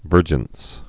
(vûrjəns)